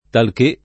talché [